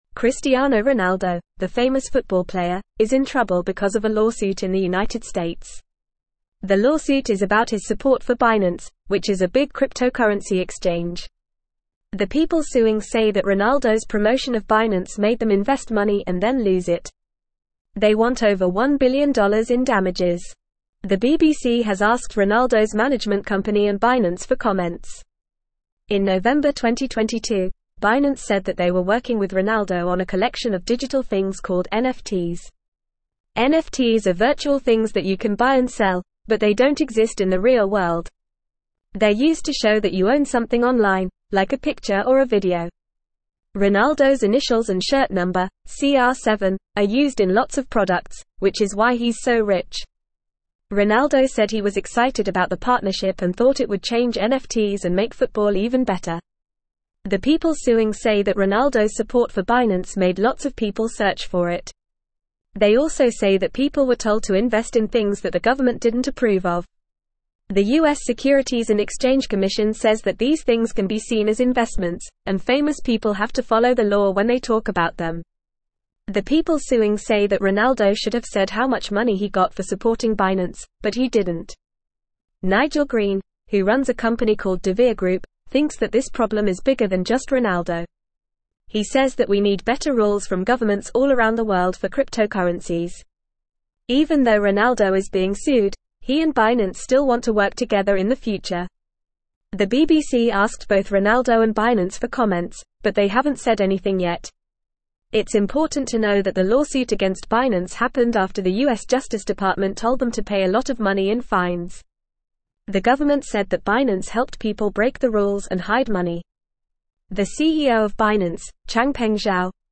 Fast
English-Newsroom-Upper-Intermediate-FAST-Reading-Cristiano-Ronaldo-Faces-1-Billion-Lawsuit-Over-Binance-Endorsement.mp3